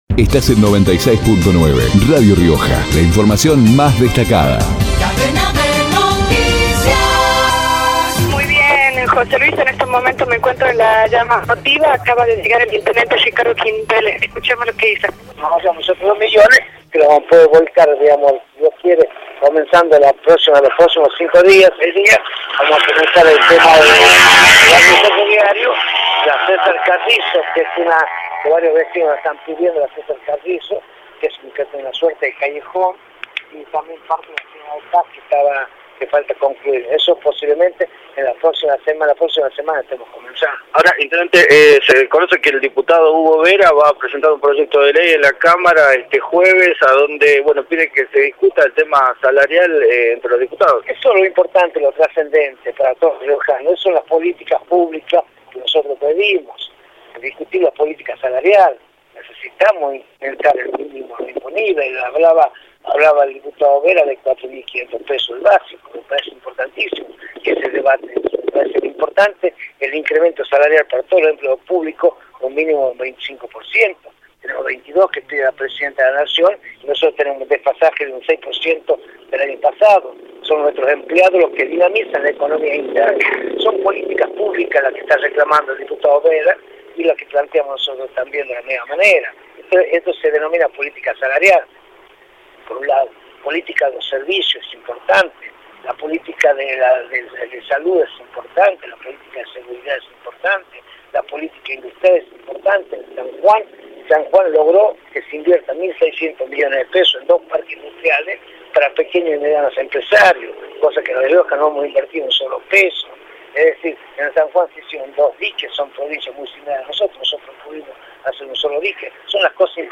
Ricardo Quintela, intendente, por Radio Rioja